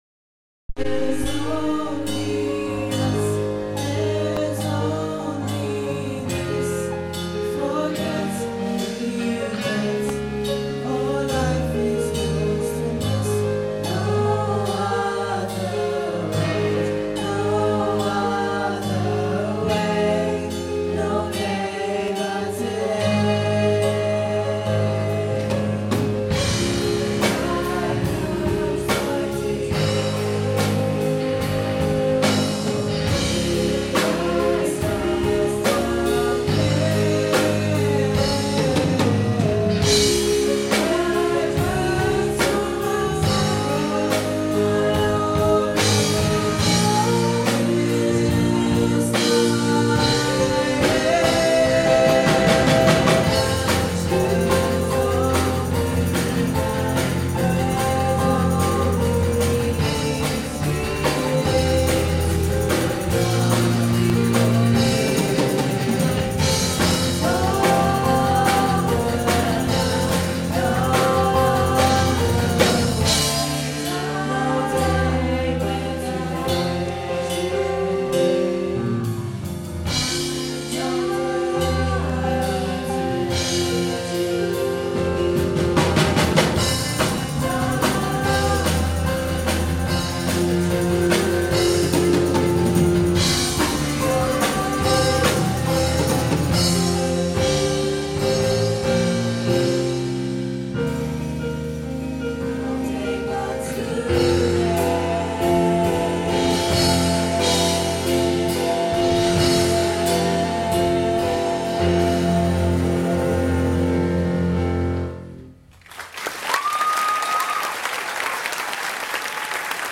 musical theatre